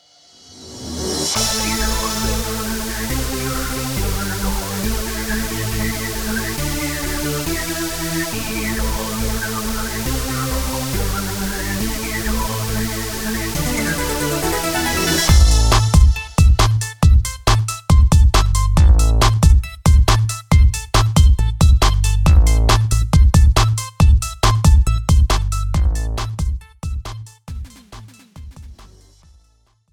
This is an instrumental backing track cover.
• Key – Am
• Without Backing Vocals
• No Fade